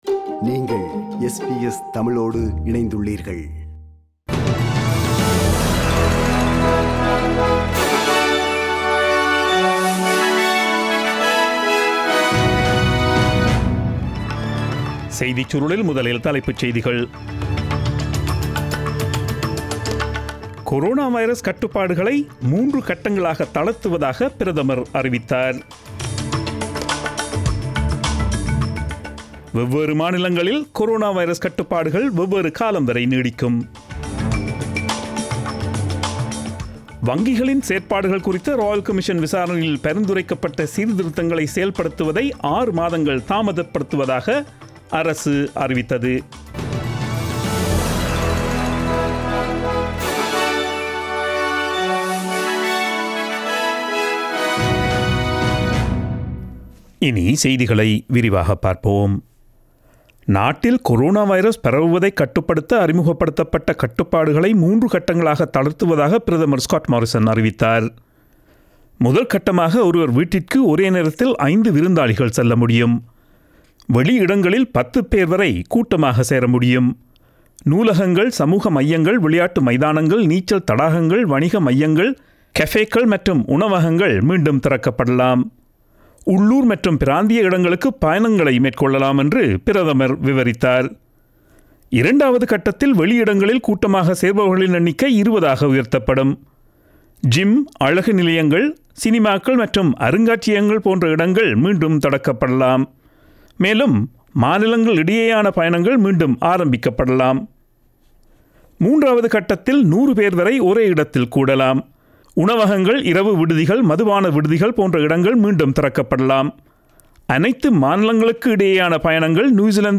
Australian news bulletin aired on Friday 08 May 2020 at 8pm.